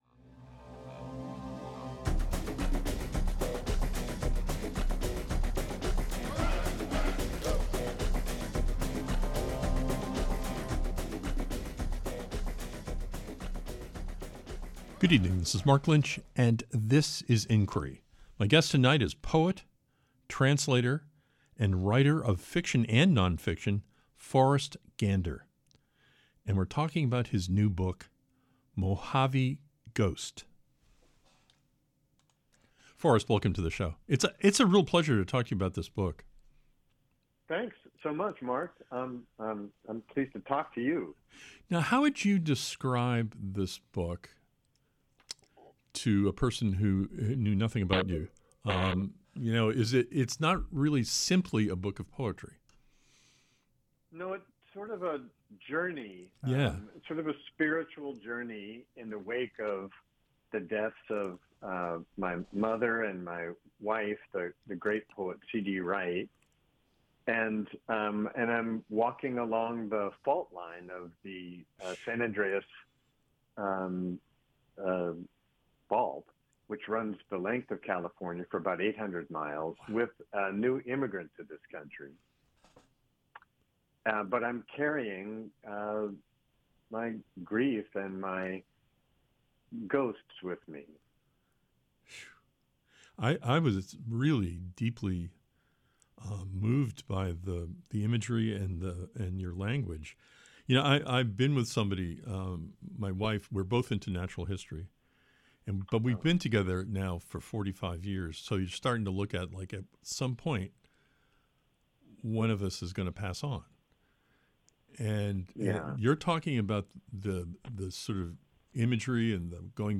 Tonight on Inquiry, we talk with Pulitzer Prize-winning poet, translator, and writer of fiction and nonfiction, FORREST GANDER about his new book MOJAVE GHOST.